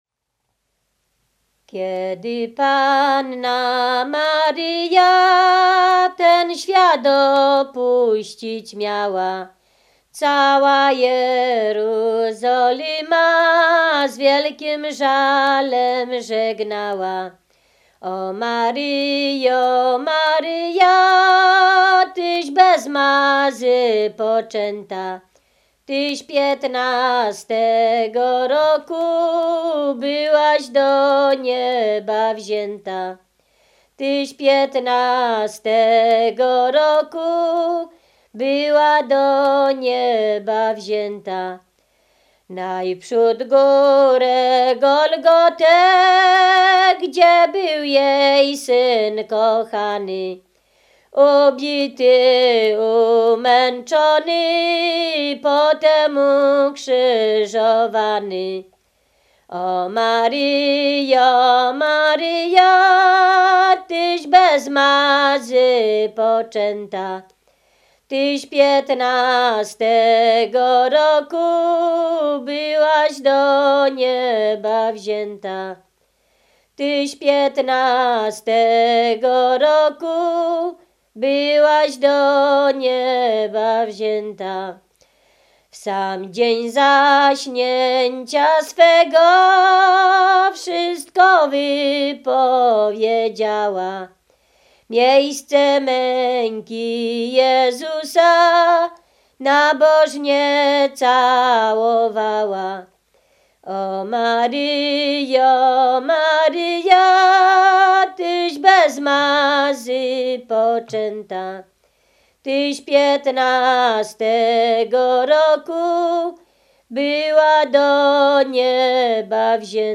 Ziemia Radomska
maryjne nabożne katolickie